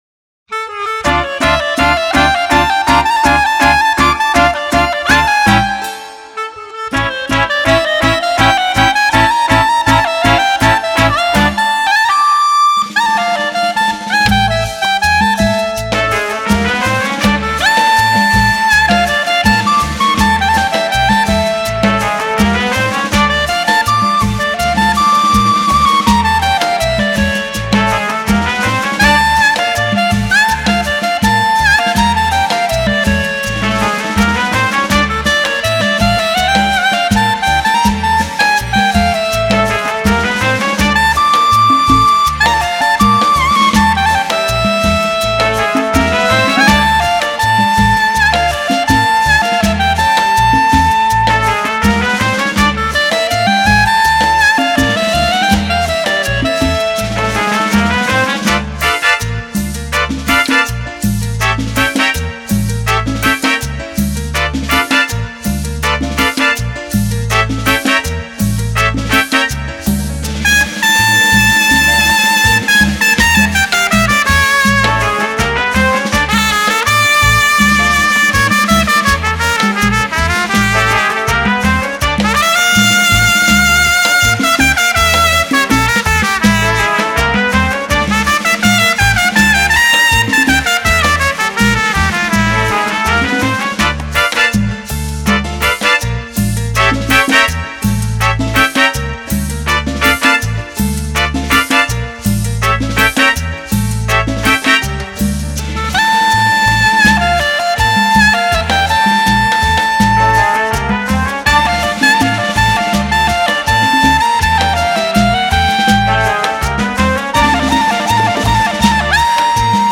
Лучший мировой инструментал